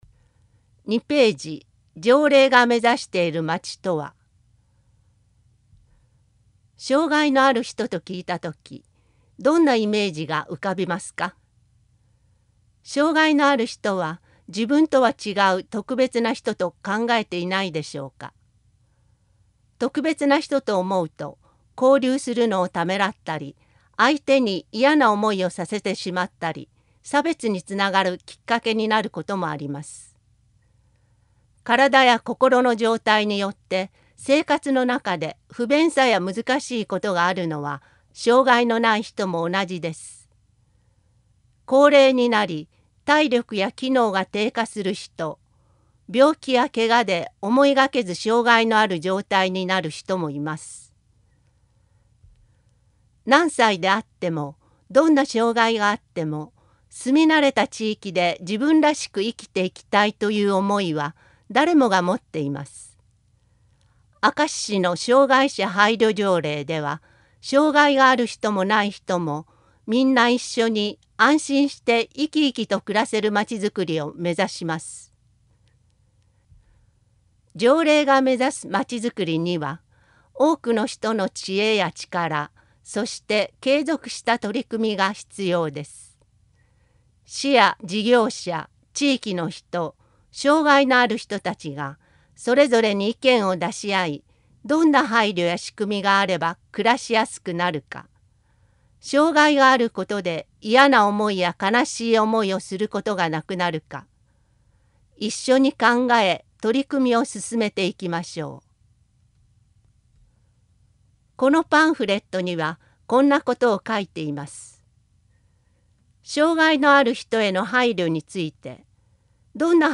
条例啓発用パンフレット（音訳版）